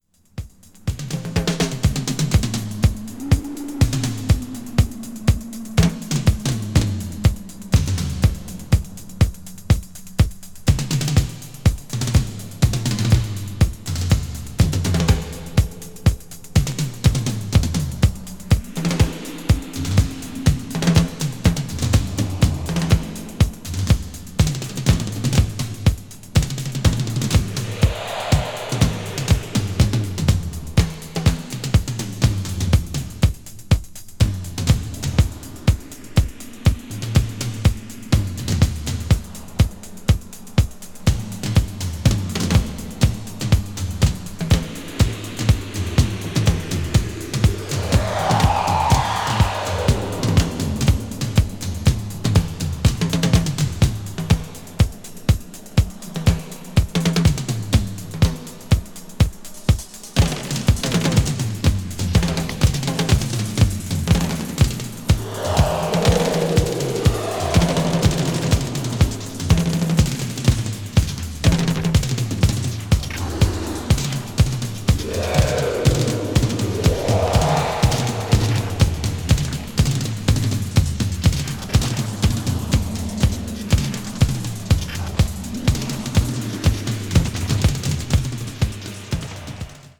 boogie disco   disco classic   french disco   synth disco